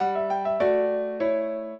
piano
minuet14-4.wav